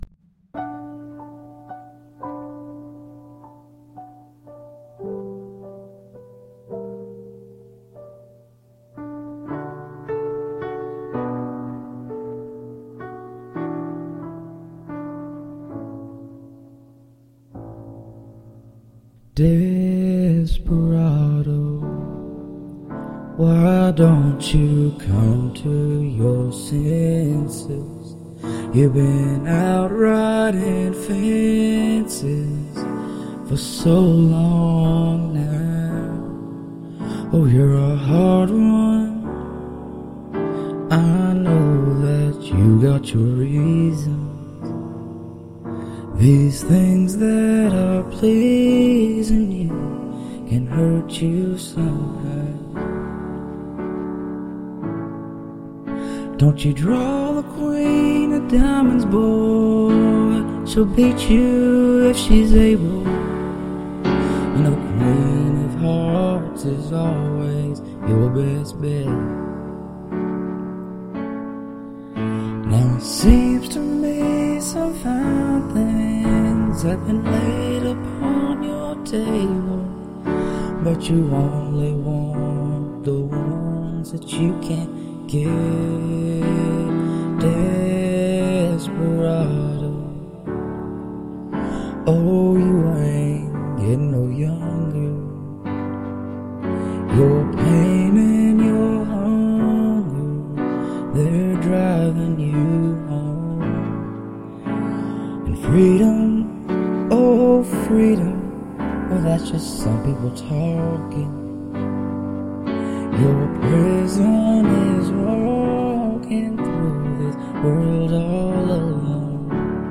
I auto-tuned my voice quite a bit if you can't tell.